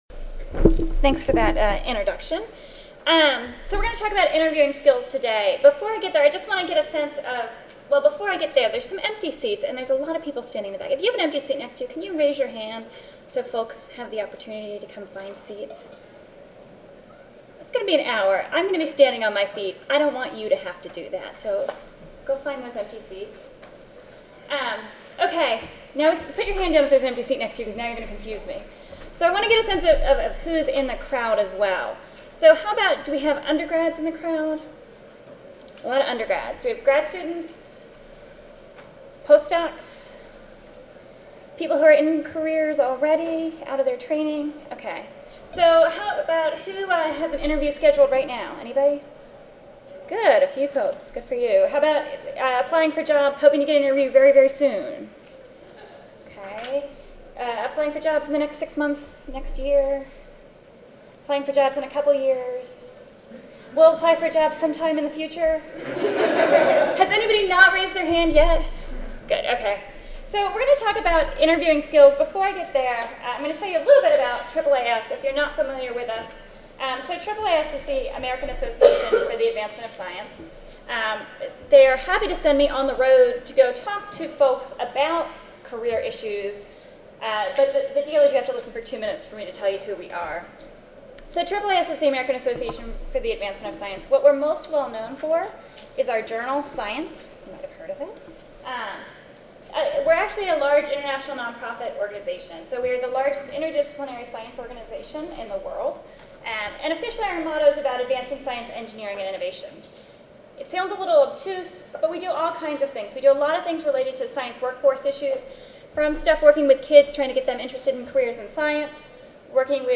1:30 PM-2:30 PM Long Beach Convention Center, Room 203C, Second Floor Share
Science Careers/AAAS Audio File Recorded presentation << Previous Session